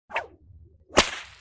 ARA_MW_Cartilage_Whip_Hit.wav